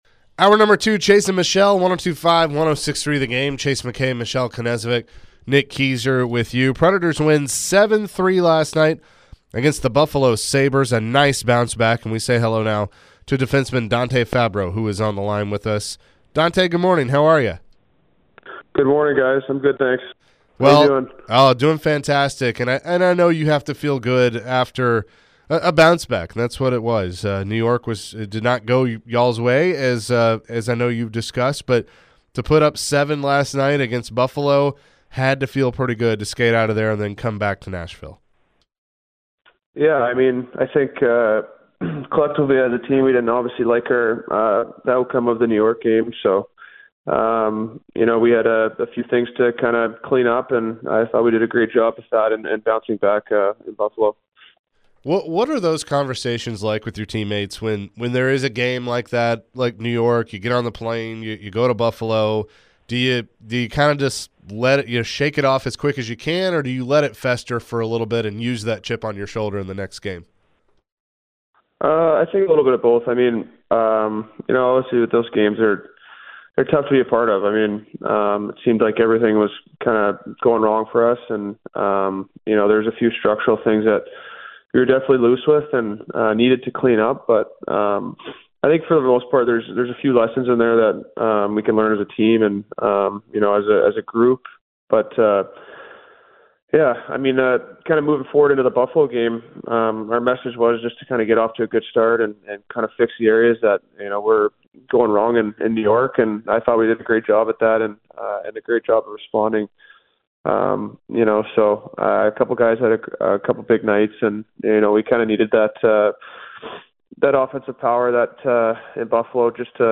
Dante Fabbro Interview (3-22-23)